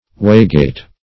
Waygate \Way"gate`\, n. The tailrace of a mill.